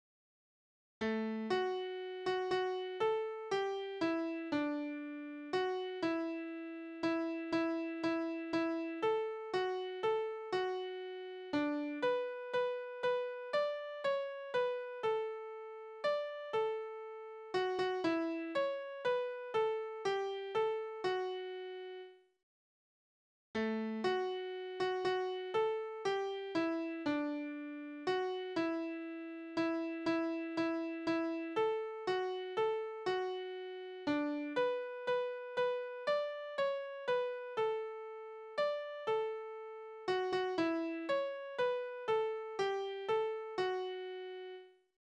Naturlieder
Tonart: D-Dur
Taktart: 3/4
Tonumfang: Undezime
Besetzung: vokal